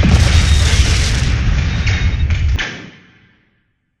missilehit.wav